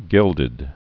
(gĭldĭd)